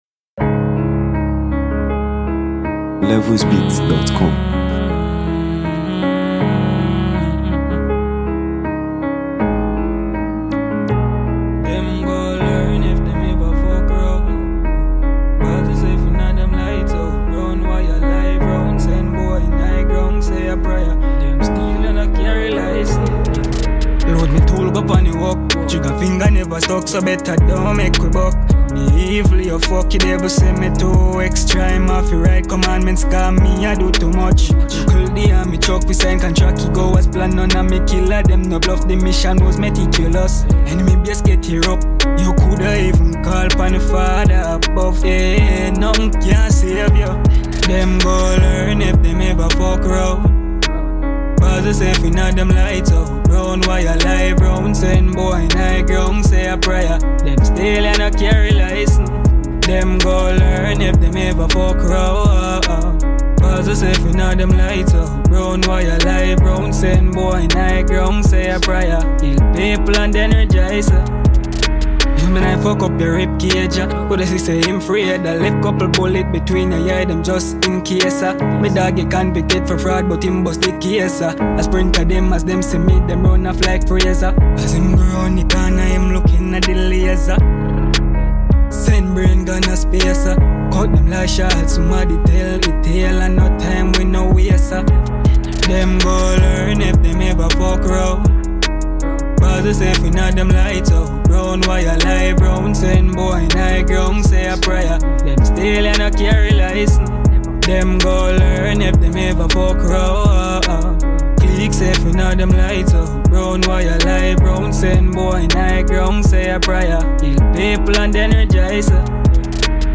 On this smooth and catchy record
delivers confident vocals and sharp lyrics